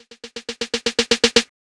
DJ Toomp Snare Roll.wav